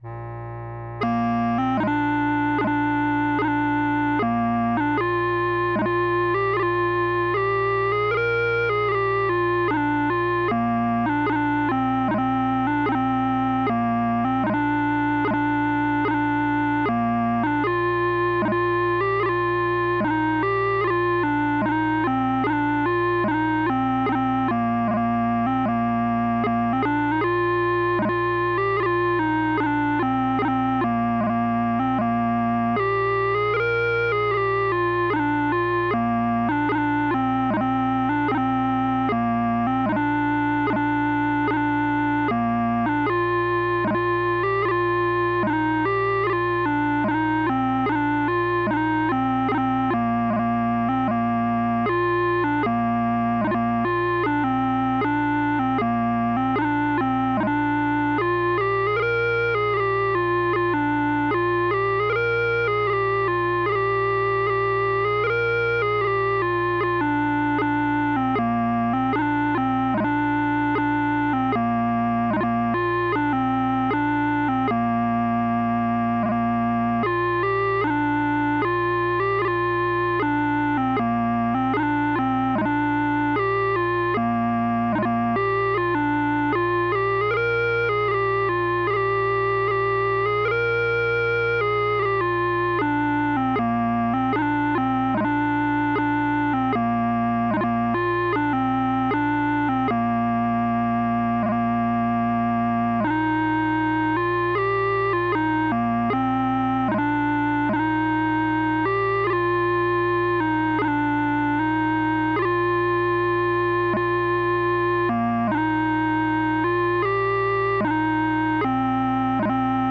PLEASE MAKE YOUR PRACTICE CHANTER PITCH MATCH THESE MP3 FILES.